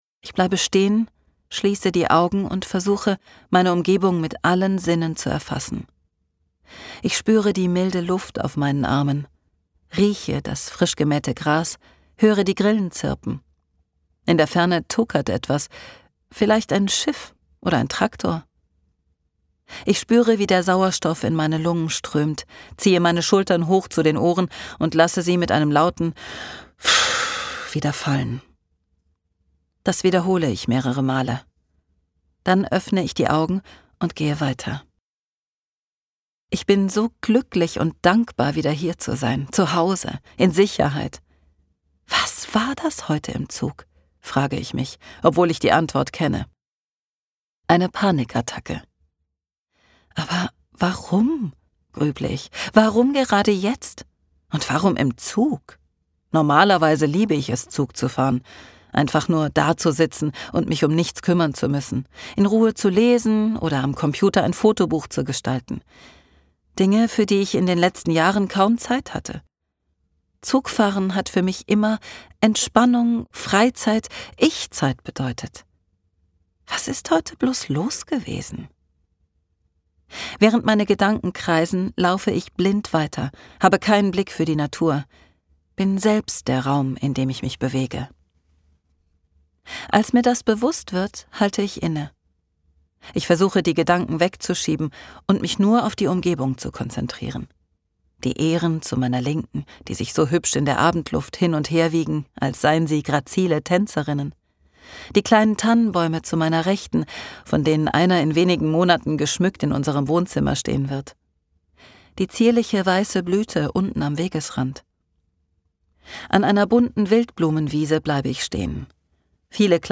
Details zur Sprecherin